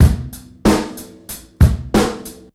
Feesible beat.wav